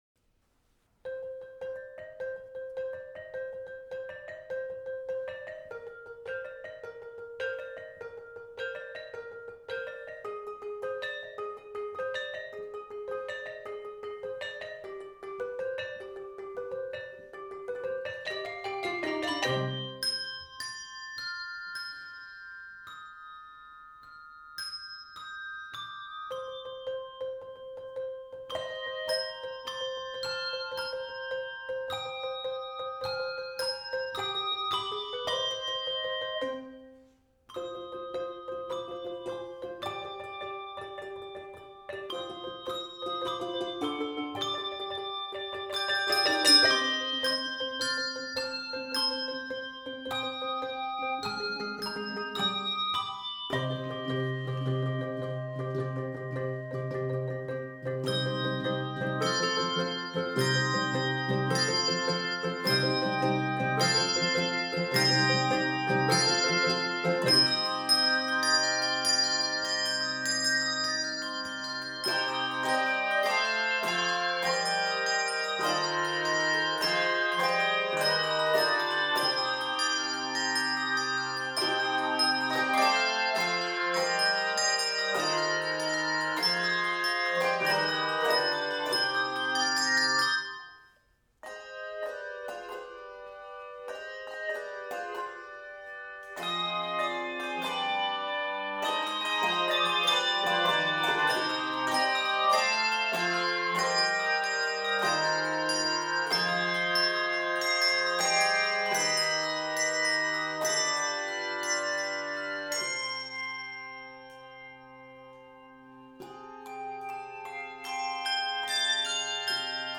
This energetic setting of the well-known Christmas tune